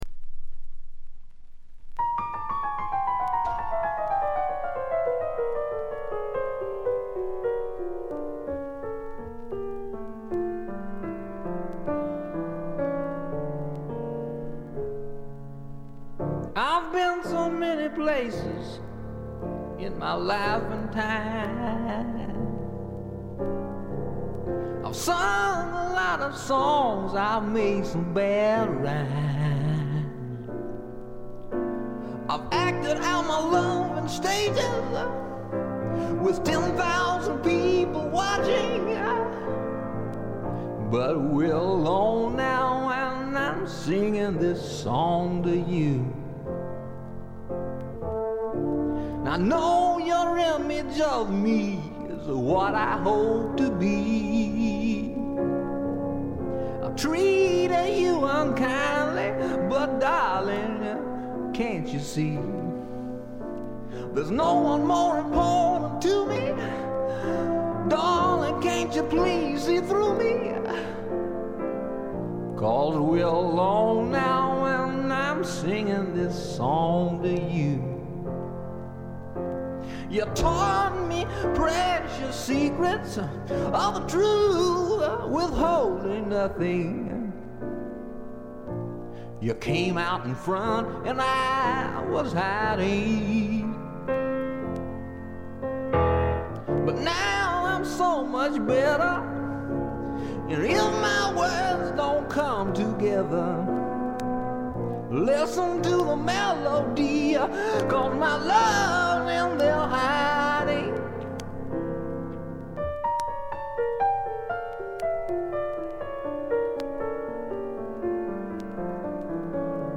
微細なチリプチ程度。
内容はいうまでもなくスワンプ風味の効いた素晴らしいシンガー・ソングライター・アルバム。
試聴曲は現品からの取り込み音源です。
piano, guitar, bass guitar, vocals